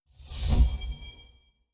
Resurrect Effect Sound.wav